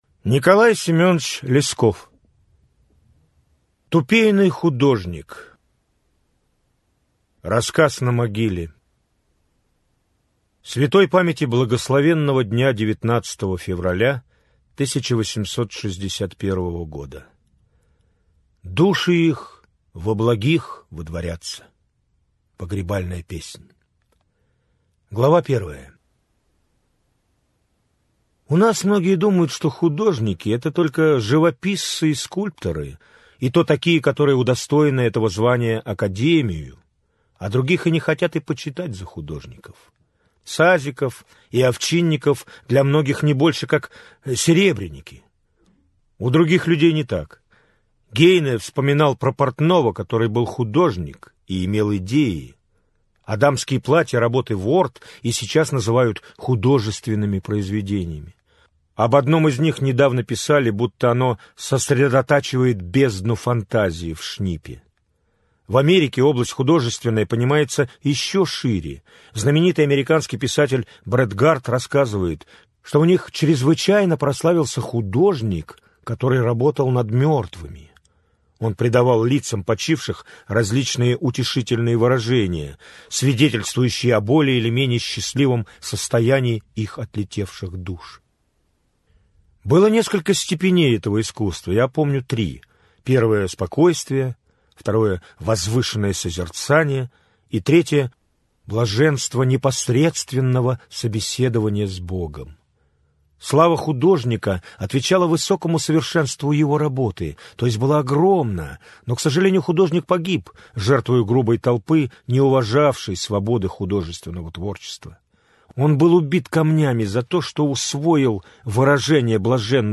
Аудиокнига Леди Макбет Мценского уезда. Левша. Тупейный художник | Библиотека аудиокниг